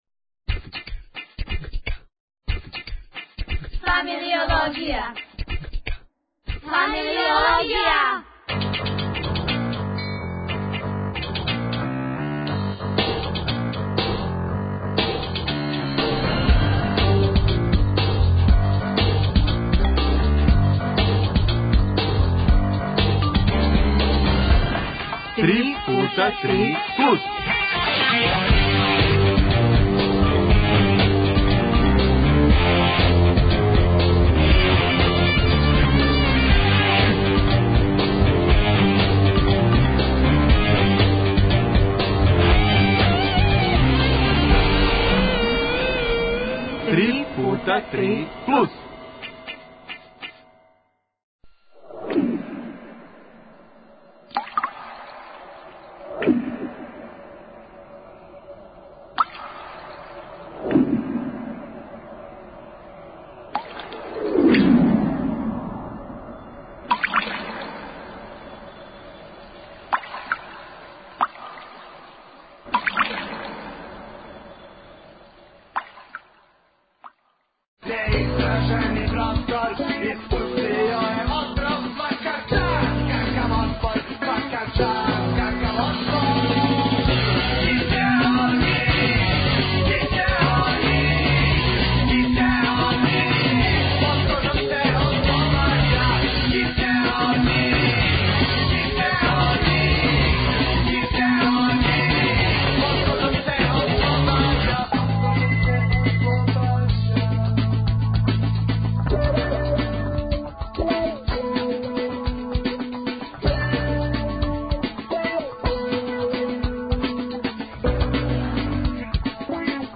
Идемо на пут око музике, музиком.